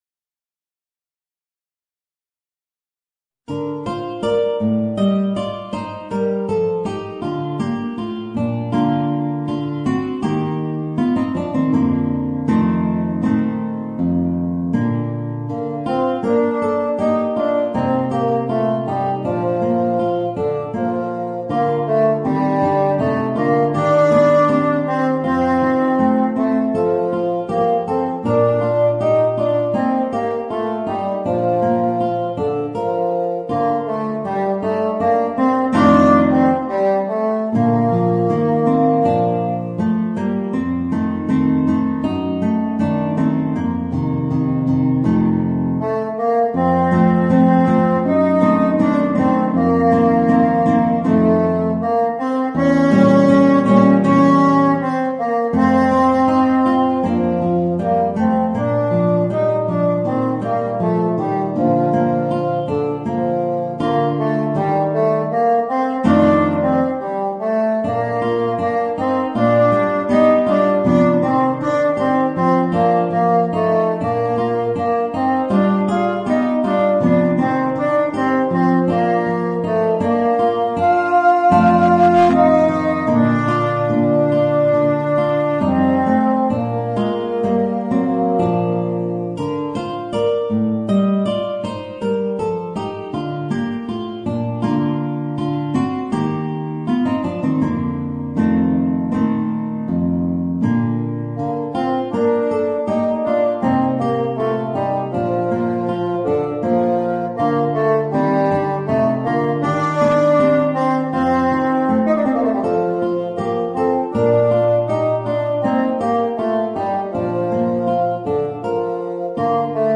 Voicing: Bassoon and Guitar